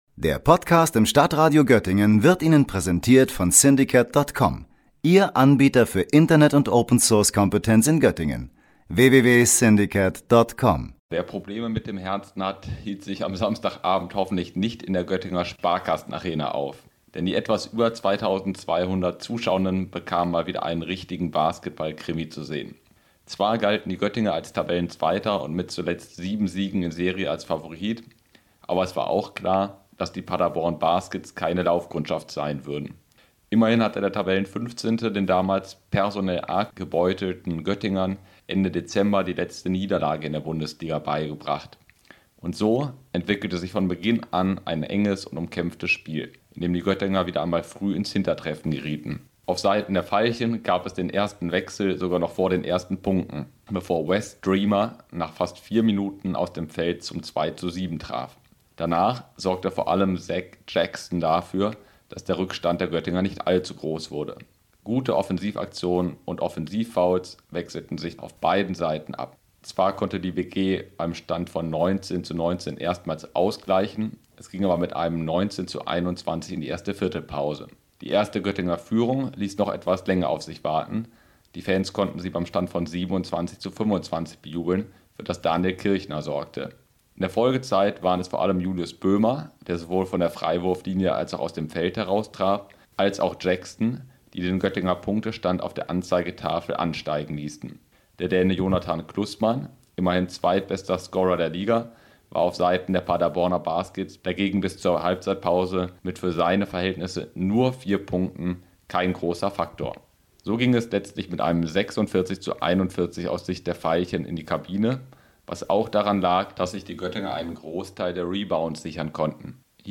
Ob die „Veilchen“ ihre jüngste Siegesserie gegen die Ostwestfalen ausbauen konnten, hat unser Reporter live vor Ort verfolgt.